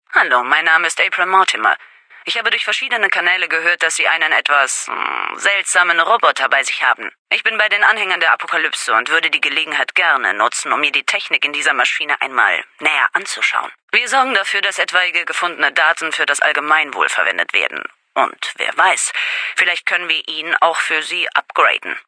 Datei:Femaleadult05 vdialoguee ederadioconvers 0015ff66.ogg
Kategorie:Fallout: New Vegas: Audiodialoge Du kannst diese Datei nicht überschreiben.